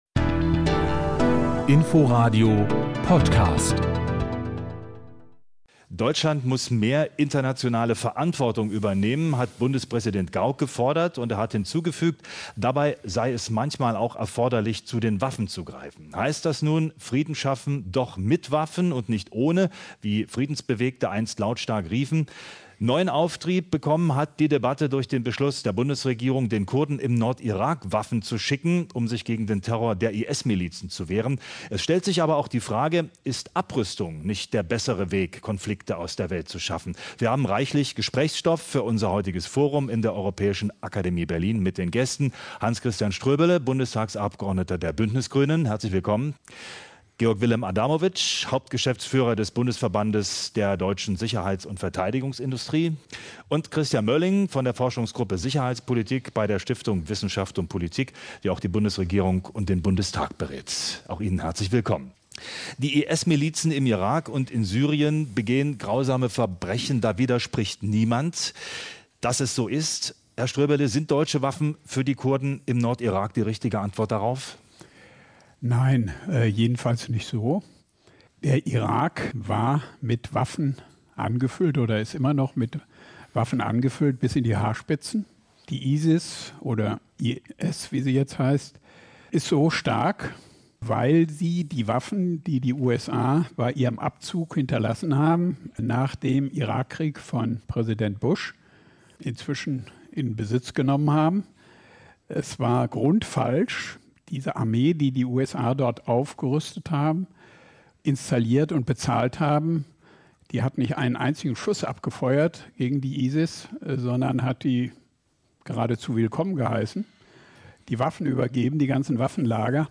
Die vollständige Podiumsdiskussion finden Sie hier.